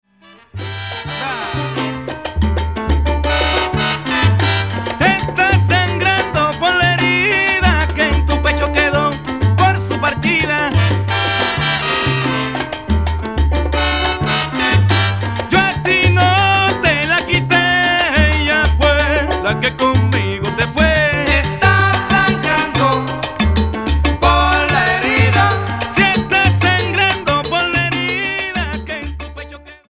Salsa Charts - Februar 2011